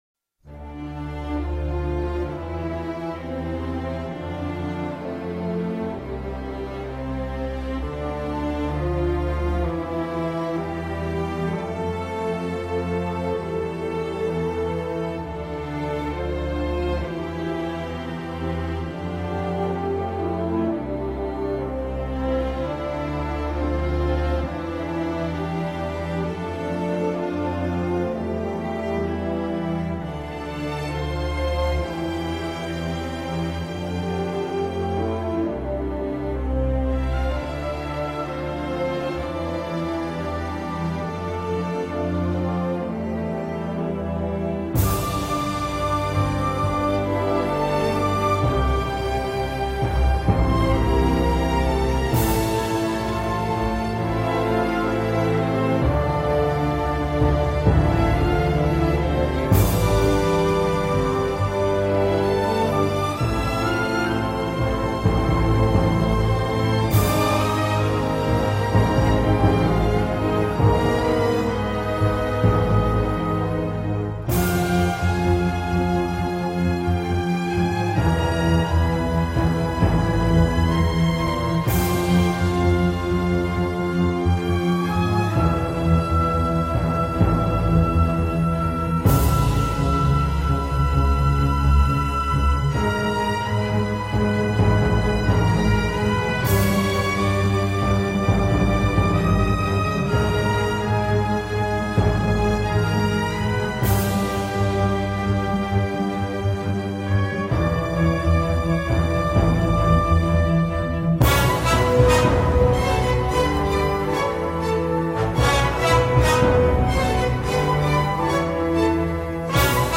بی کلام